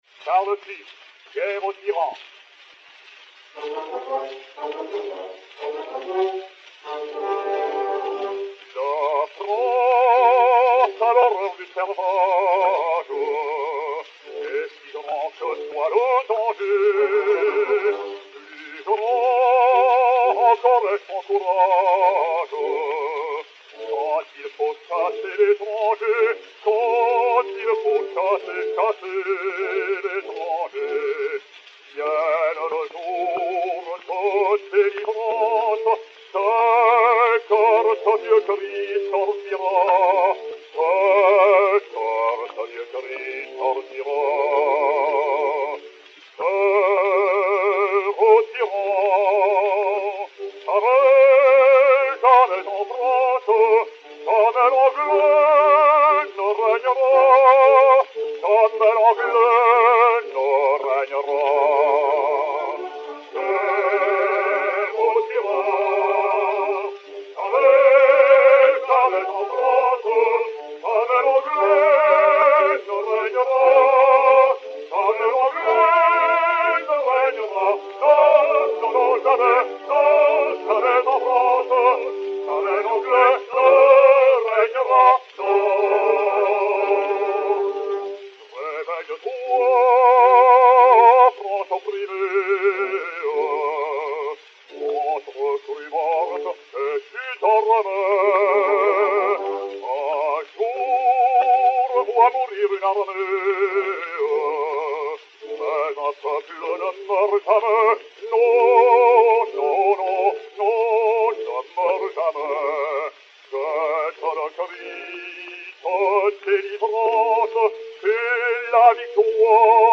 basse française
Chœurs et Orchestre